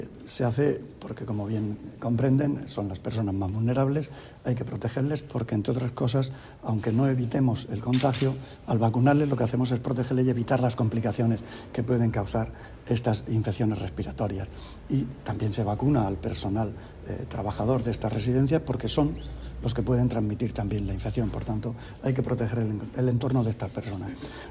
Sonido/ Declaraciones del consejero de Salud, Juan José Pedreño [mp3], sobre el inicio de la vacunación en residencias.